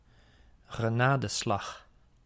Ääntäminen
IPA: ɣəˈnaːdəˌslɑɣ IPA: çəˈnaːdəˌslɑç